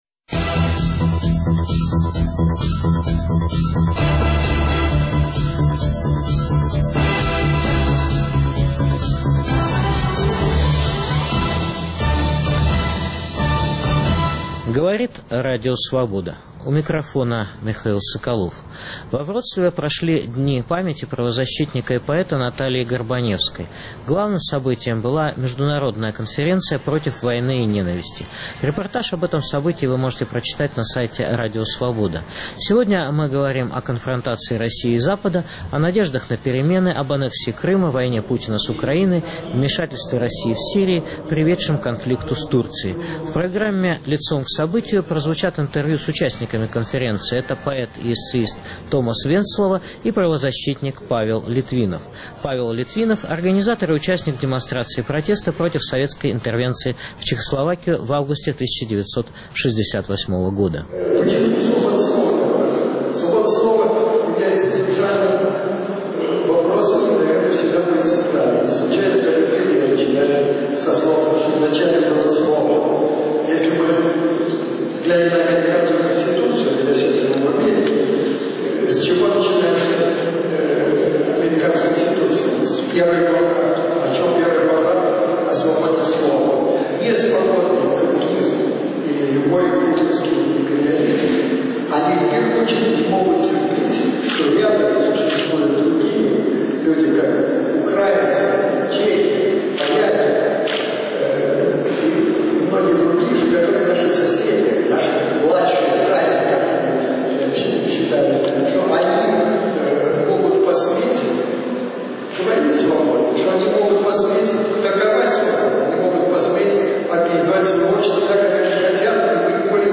В студии - правозащитник, участник демонстрации в августе 1968 года против советской интервенции в Чехословакию Павел Литвинов и литовский поэт и американский эссеист, участник диссидентского движения в СССР Томас Венцлова.